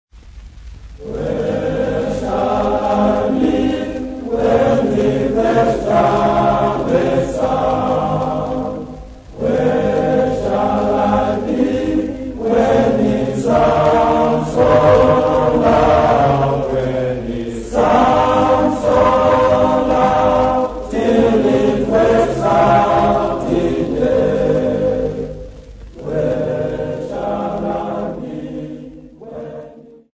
Domboshowa School boys
Popular music--Africa
Field recordings
sound recording-musical
Indigenous music